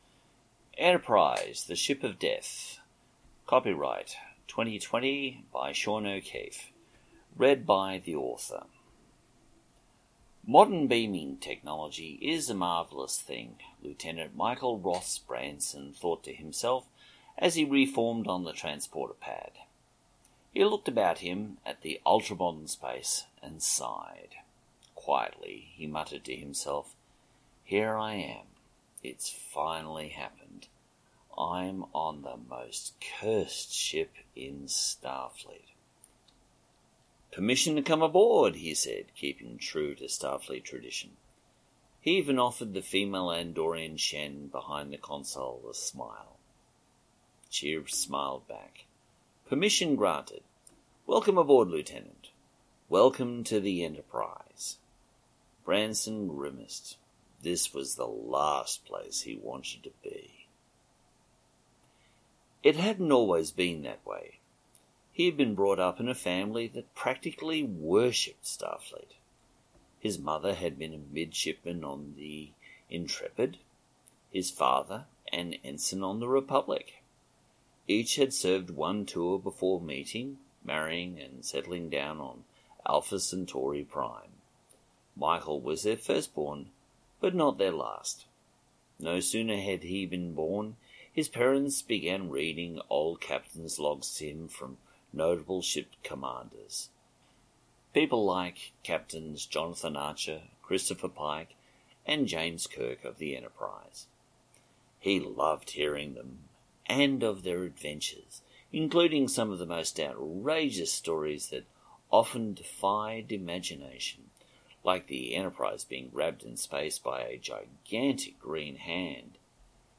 Audio Books/Drama Author(s